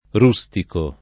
rustico [ r 2S tiko ]